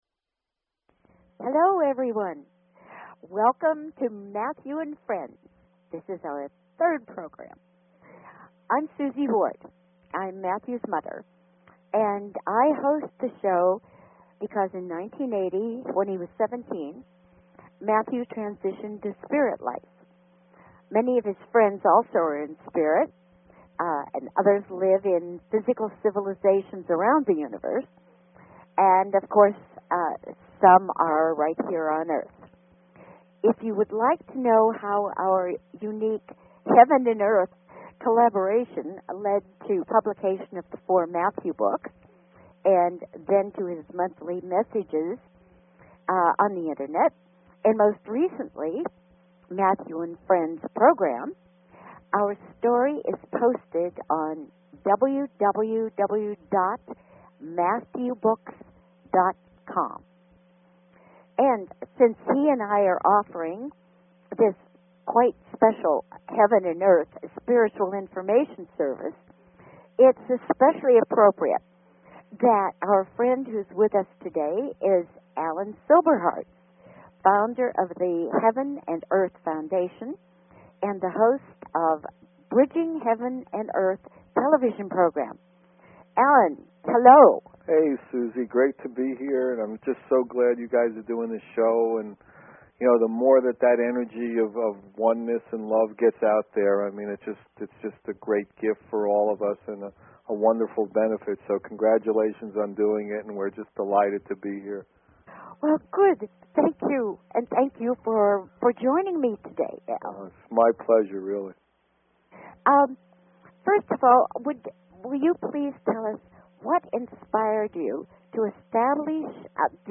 Talk Show Episode, Audio Podcast, Matthew_and_Friends_Hour and Courtesy of BBS Radio on , show guests , about , categorized as